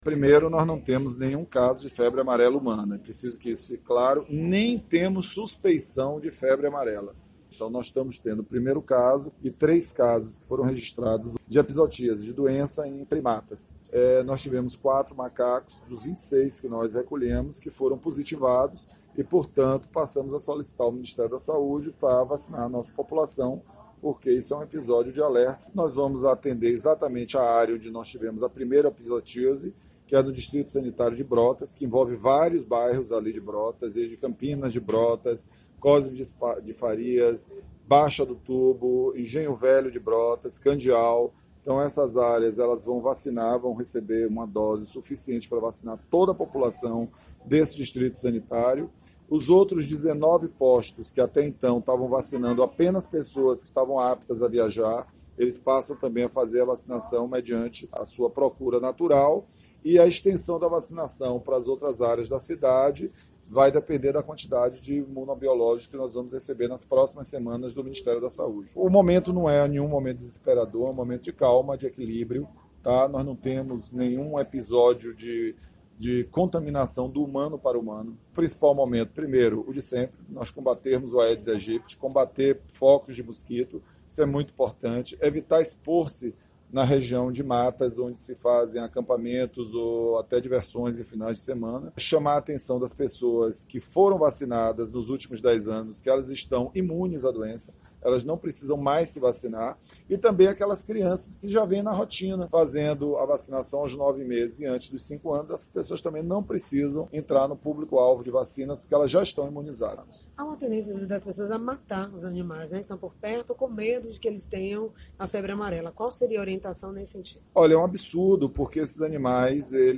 O secretário Municipal da Saúde, José Antônio Rodrigues Alves, afirmou durante a coletiva para imprensa nesta quarta-feira (29.03) que o momento não é para pânico, mas de alerta: “não existe caso de febre amarela humana e nem suspeita da doença em humanos.
SONORA-SECRETARIO-JOSE-ANTONIO-006.mp3